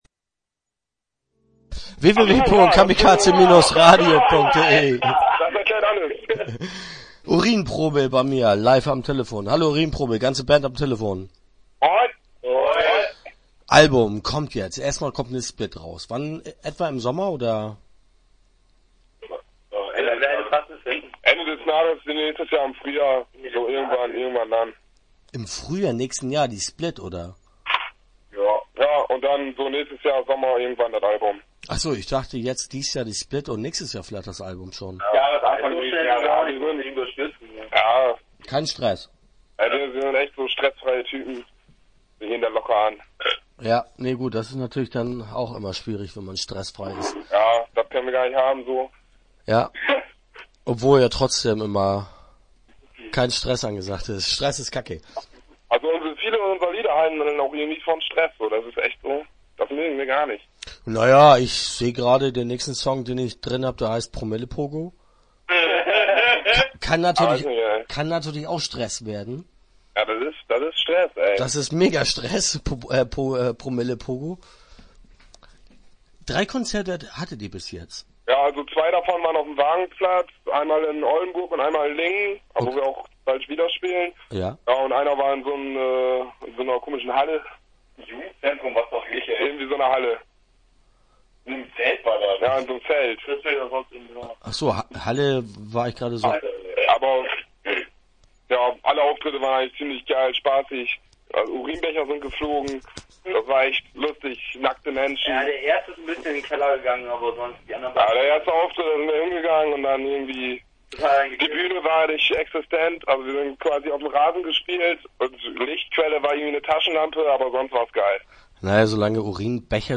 Start » Interviews » Urinprobe